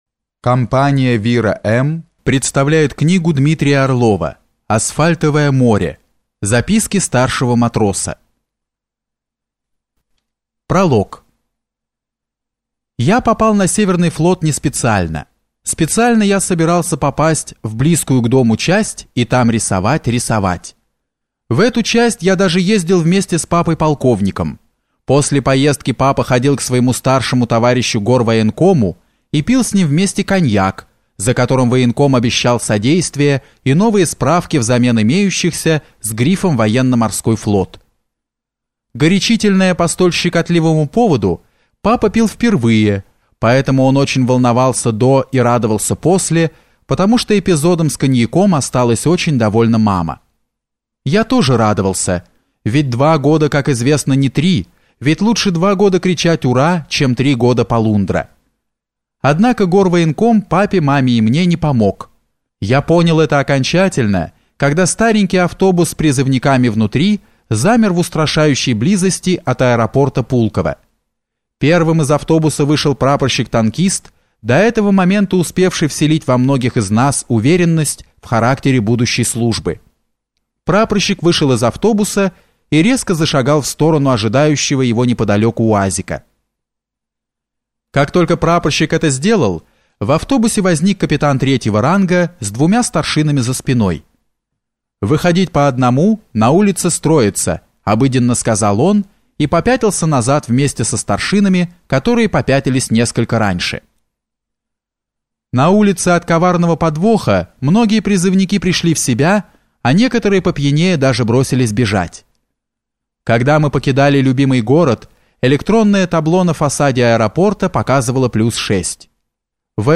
Аудиокнига Асфальтовое море | Библиотека аудиокниг